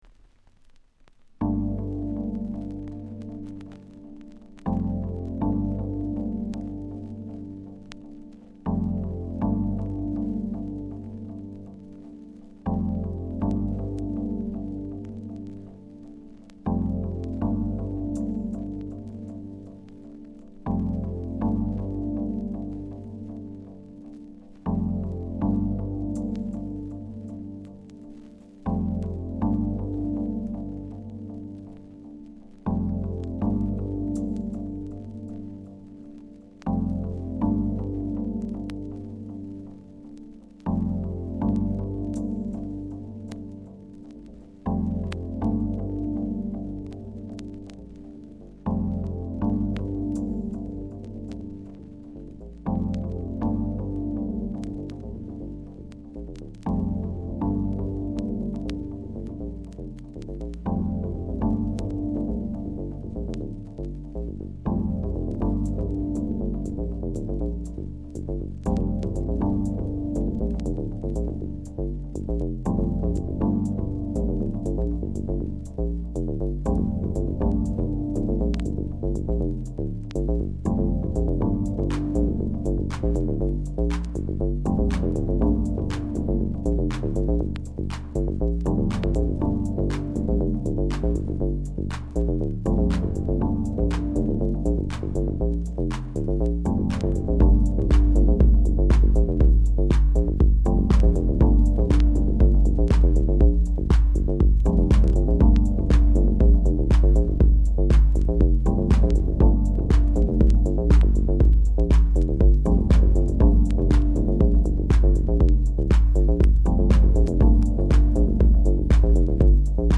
House / Techno
ミニマルで耽美的な世界に誘うミラクルトラック。